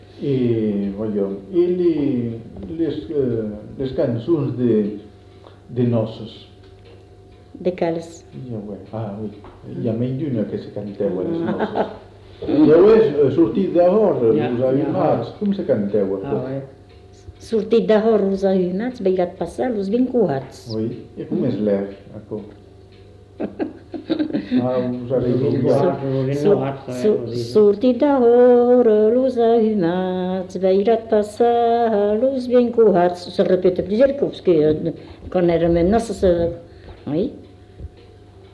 Genre : chant
Effectif : 1
Type de voix : voix de femme
Production du son : chanté
Classification : danses